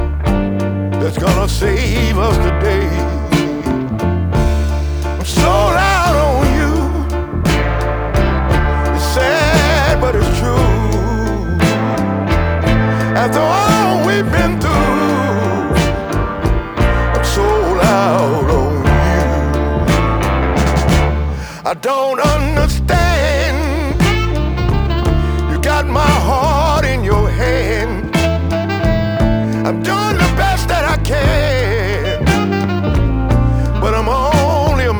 # Blues